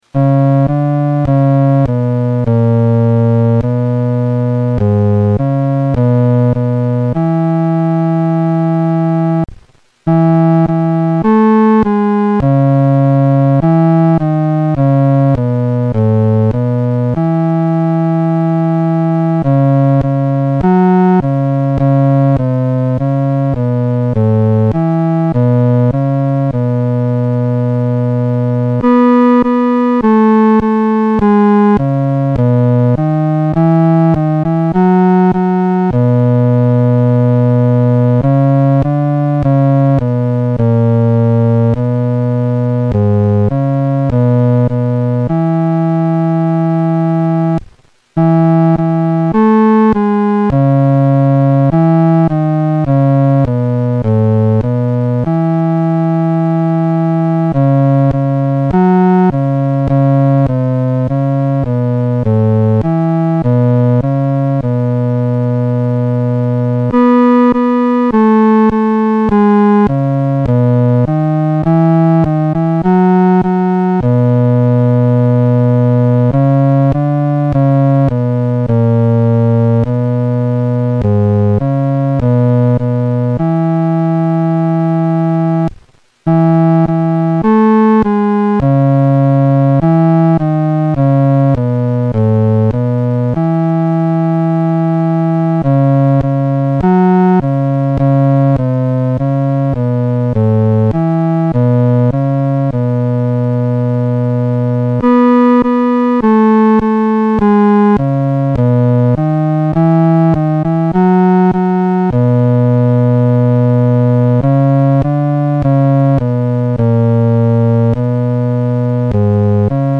伴奏
男低
这首诗歌宜用中庸的速度来弹唱。